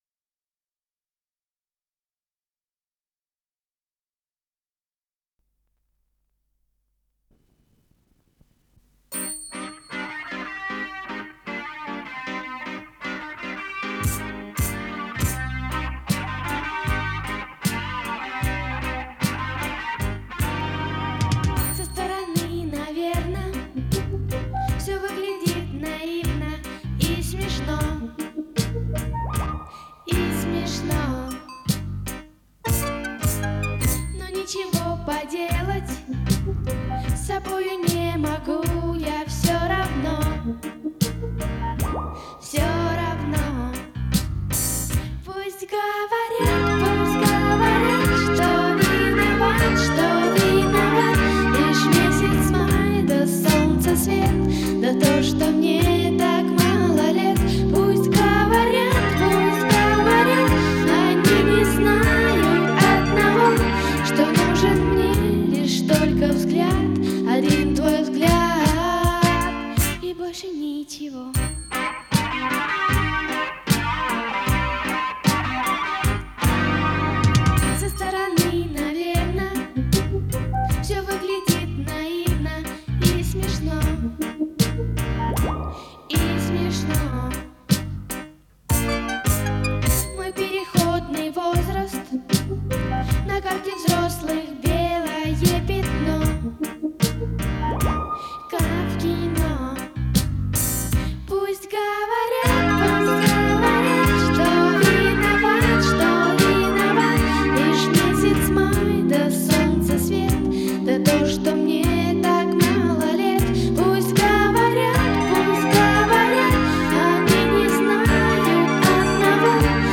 с профессиональной магнитной ленты
Скорость ленты38 см/с
МагнитофонМЭЗ-109М